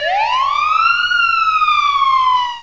SIREN.mp3